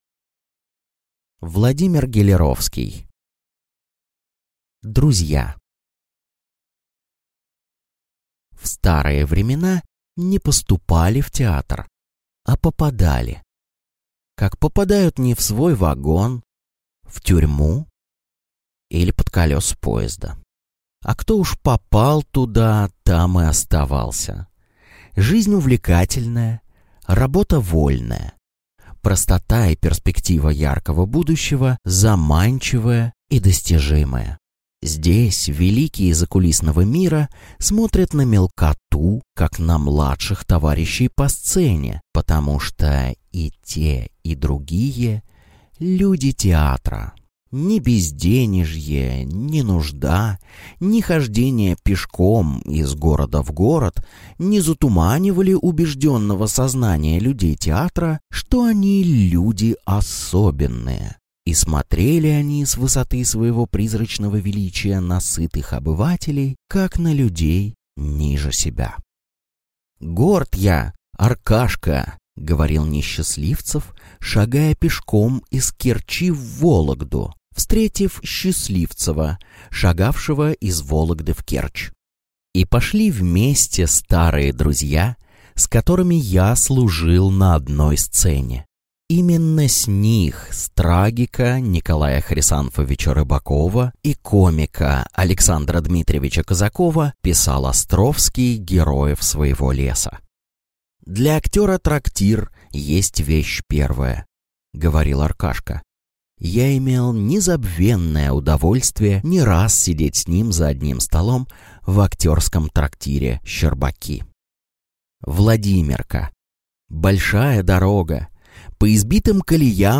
Аудиокнига Друзья | Библиотека аудиокниг